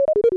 made terminal sounds quieter